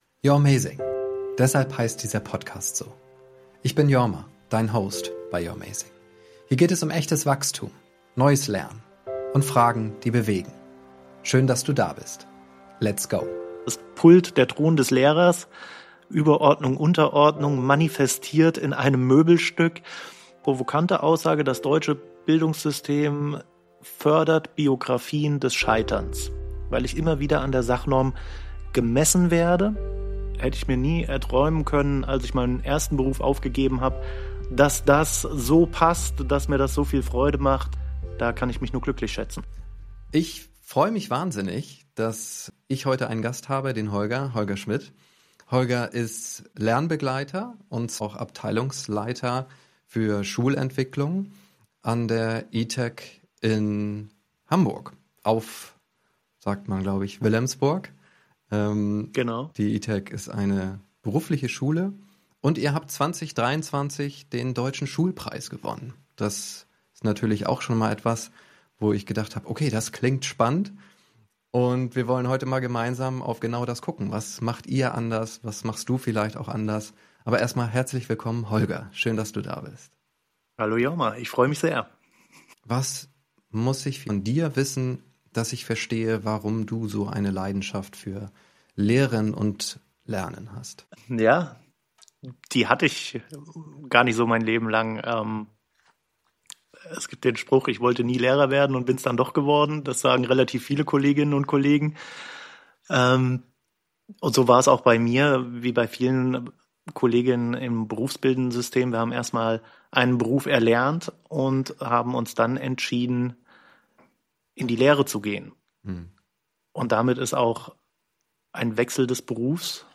In diesem Gespräch geht es nicht um Theorie, sondern um echte Veränderung.